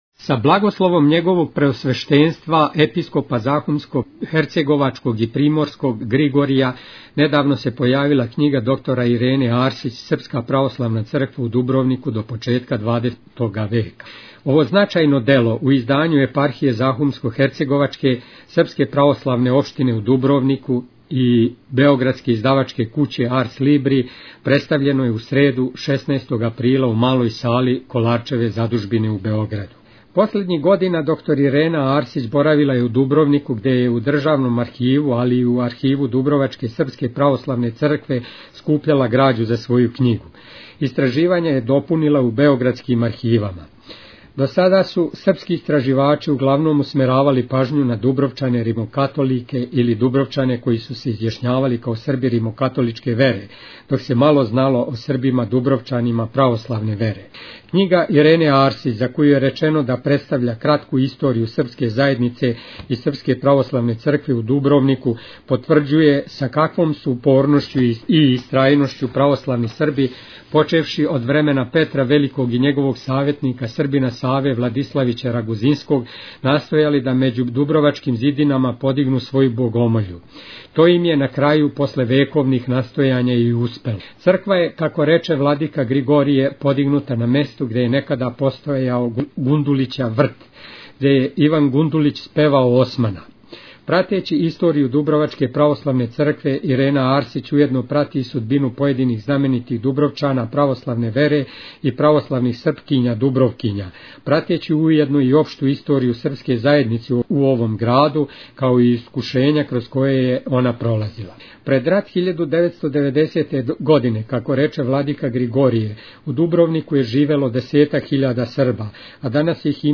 Његово Преосвешетнство Епископ захумско-херцеговачки Г. Григорије представио је књигу о Православној Цркви у Дубровнику